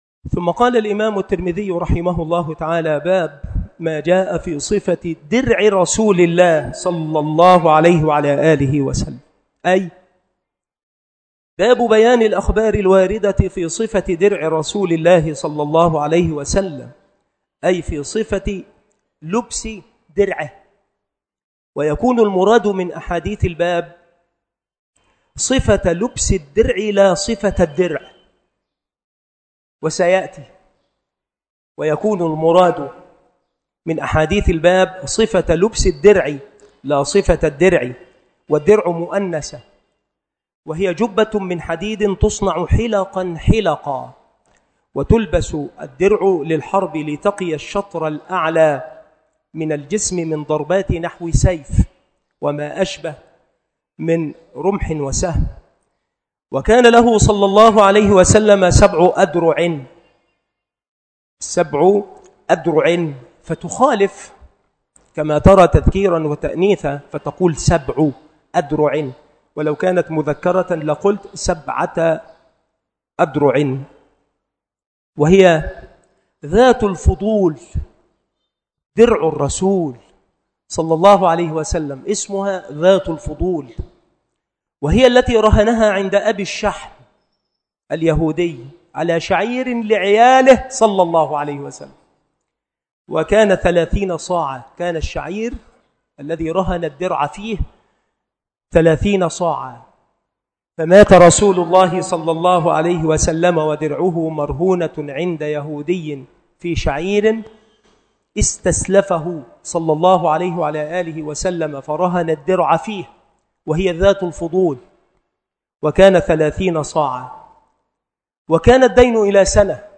شروح الحديث
مكان إلقاء هذه المحاضرة بالمسجد الشرقي بسبك الأحد - أشمون - محافظة المنوفية - مصر